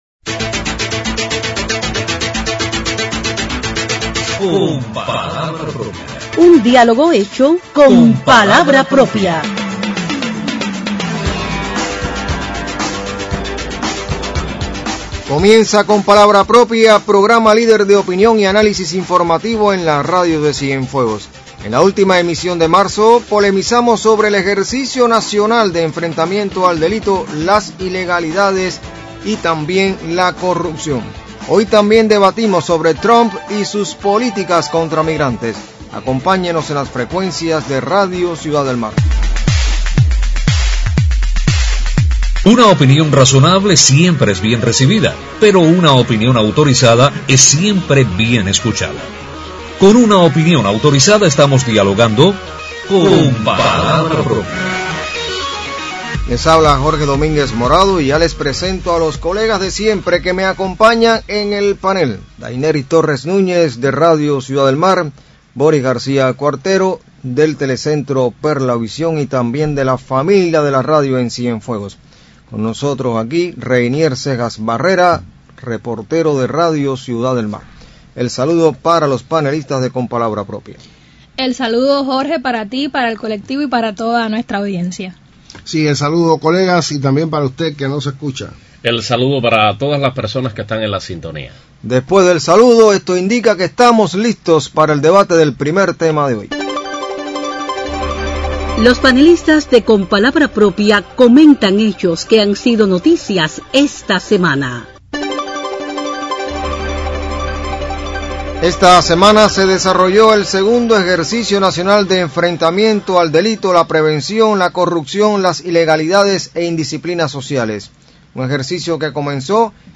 Sobre la necesidad de que el enfrentamiento al delito, la corrupción e ilegalidades resulten una acción de todos los días y «sin avisar», comentan los panelistas de Con palabra propia en la emisión del sábado 29 de marzo.